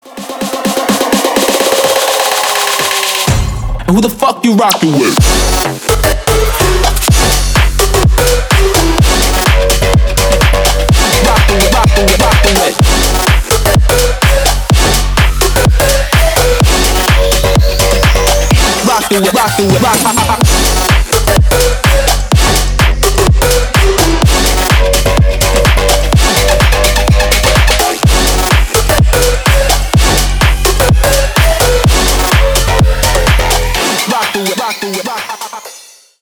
• Качество: 320 kbps, Stereo
Электроника
клубные
громкие